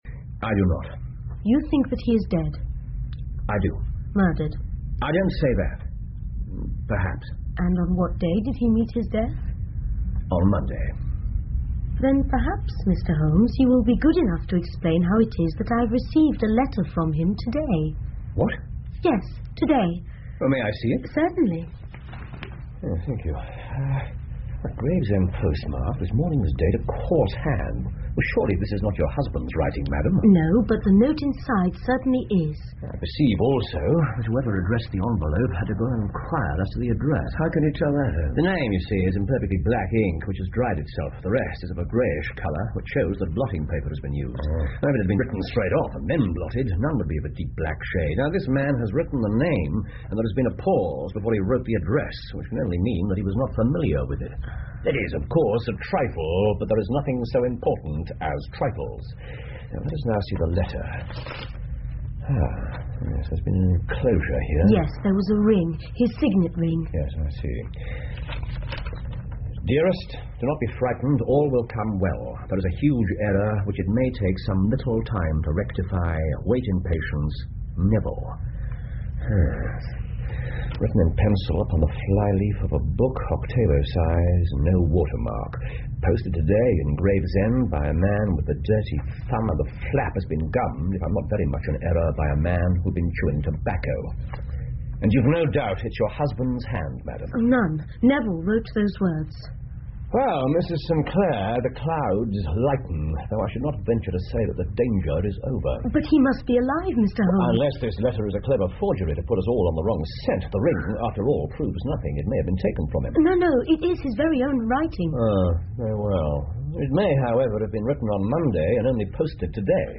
福尔摩斯广播剧 The Man With The Twisted Lip 5 听力文件下载—在线英语听力室